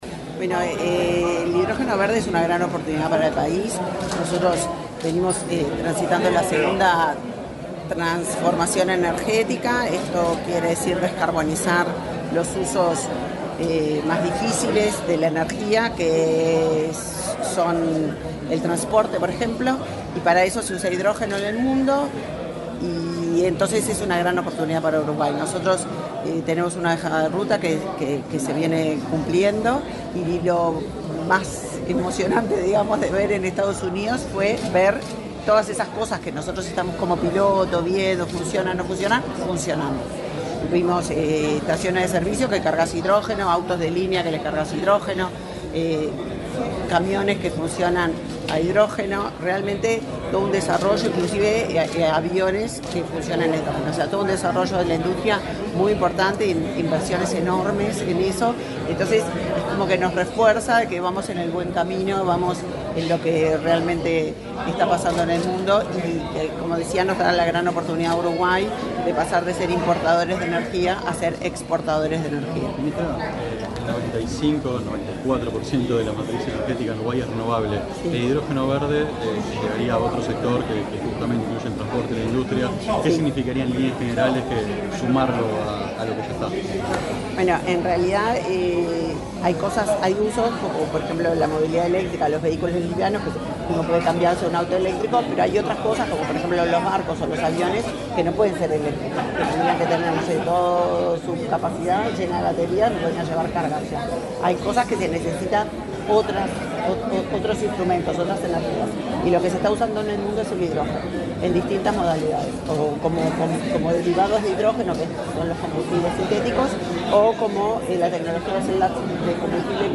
Declaraciones de la ministra de Industria, Elisa Facio
Este miércoles 25 en Montevideo, la ministra de Industria, Elisa Facio, disertó en un almuerzo de trabajo de la Asociación de Dirigentes de Marketing.
Luego, dialogó con la prensa.